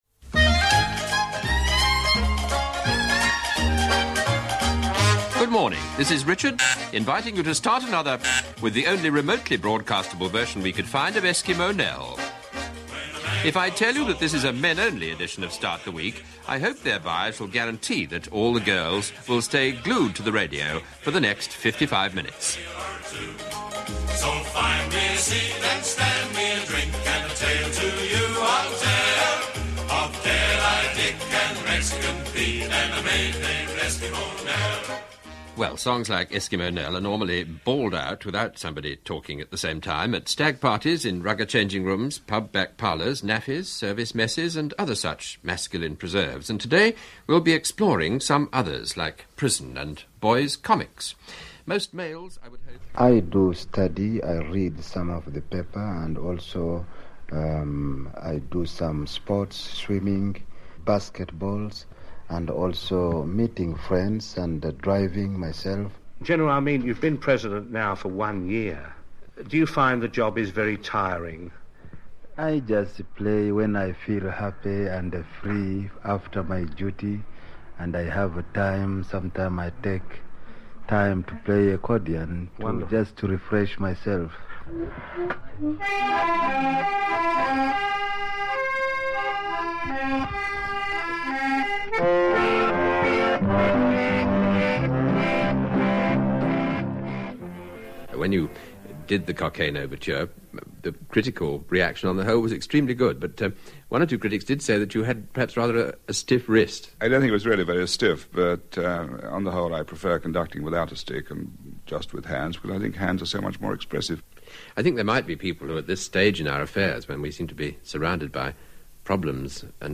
For the first 17 years of its existence the regular Monday morning host was Richard Baker. Here are a few clips from the archive. As well as Richard you’ll hear an eclectic mix of Idi Amin, Ted Heath, Kenny Everett, Sophia Loren and Kenneth Robinson.